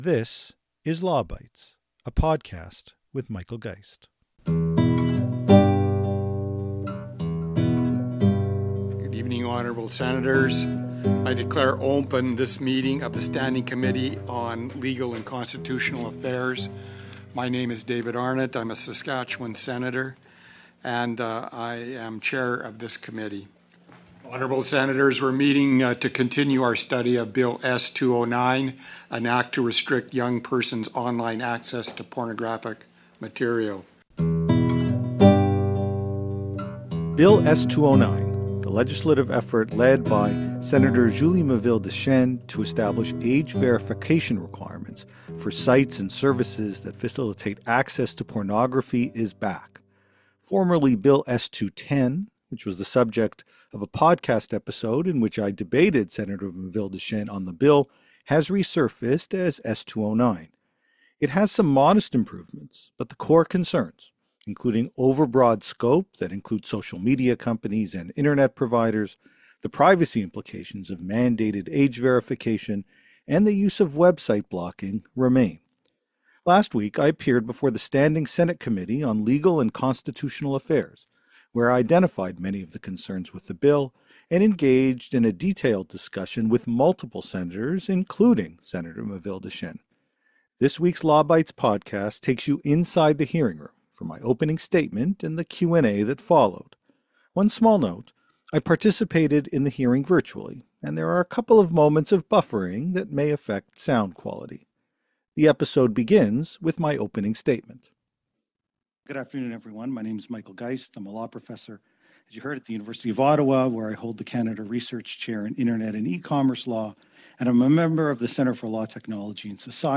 This week’s Law Bytes podcast goes inside the hearing room for my opening statement and the Q&A with Senators that followed.